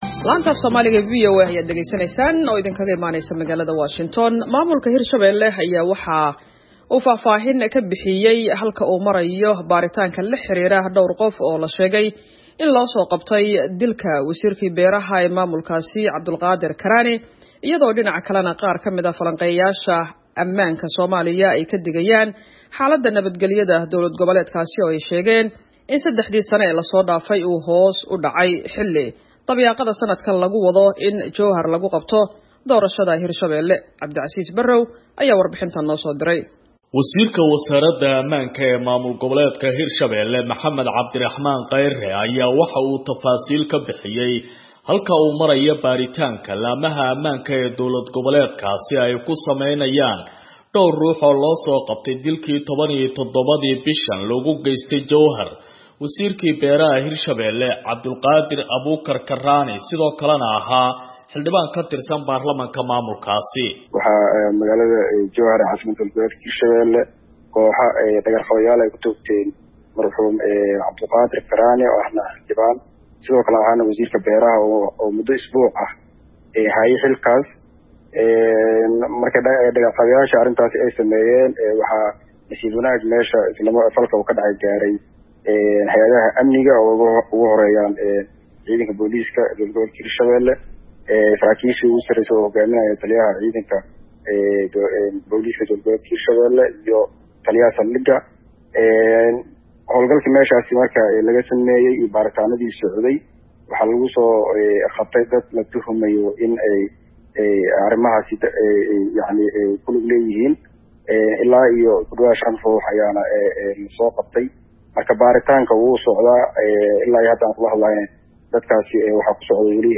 Warbixin: Ammaanka Hir-Shabeelle